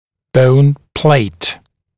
[bəun pleɪt][боун плэйт]костная пластинка; пластинка для фиксации сегментов челюсти при хирургической операции